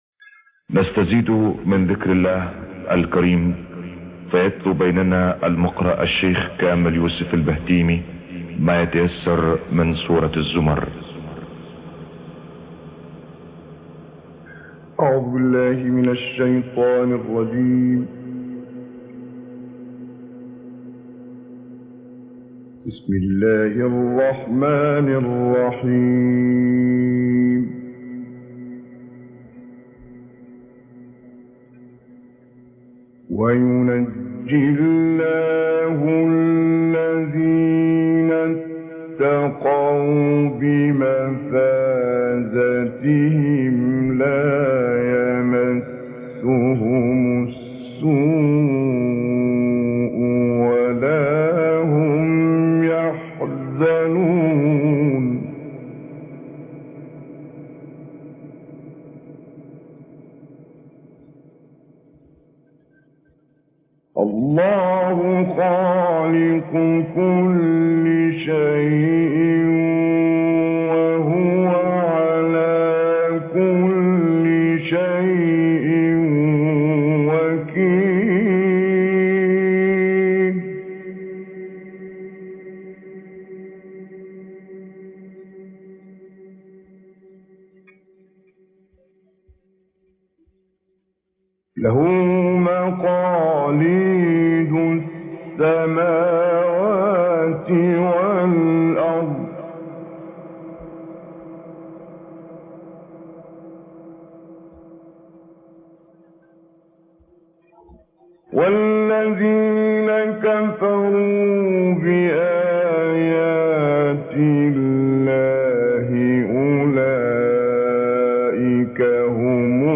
022 Surat Al Zumor.mp3